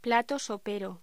Locución: Plato sopero
voz